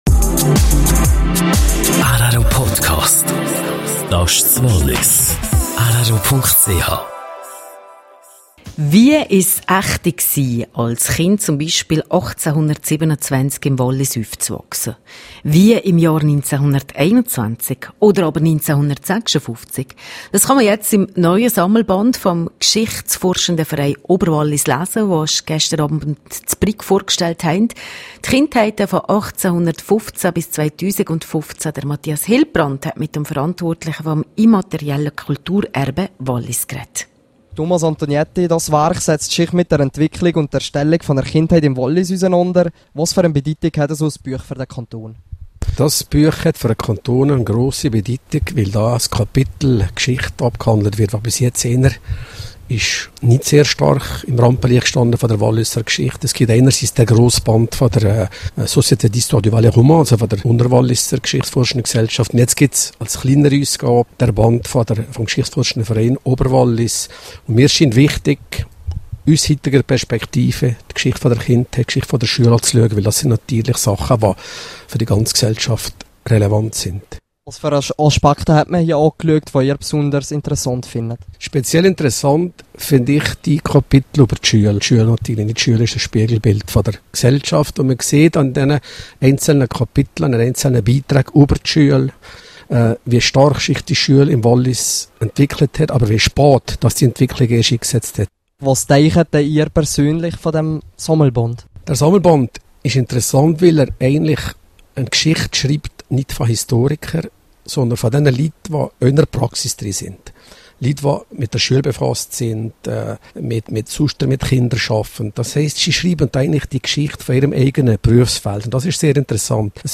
im Gespräch über das neue Werk des geschichtsforschenden Vereins Oberwallis.